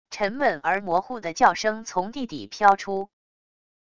沉闷而模糊的叫声从地底飘出wav音频